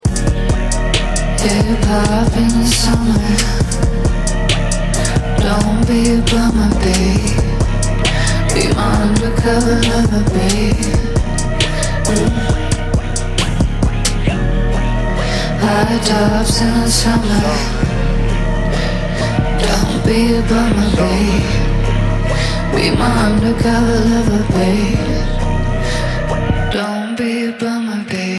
• Качество: 128, Stereo
поп
женский вокал
чувственные
indie pop
Chill